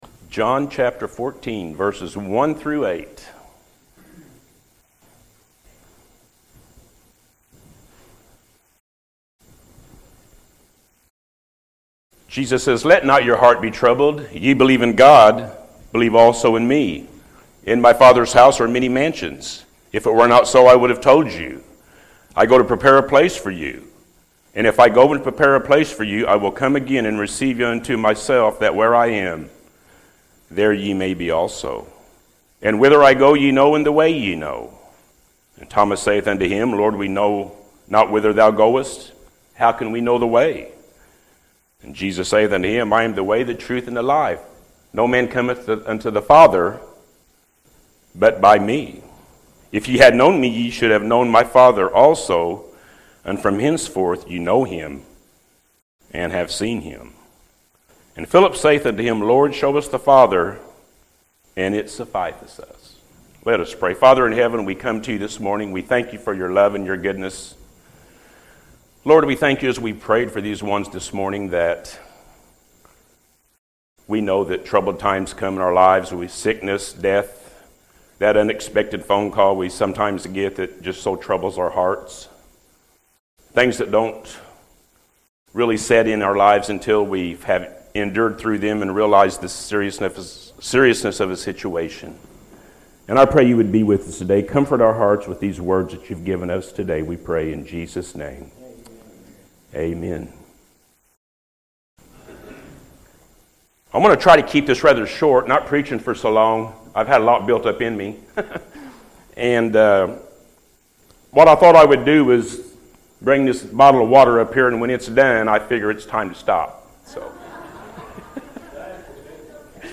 Let Not Your Heart Be Troubled-AM Service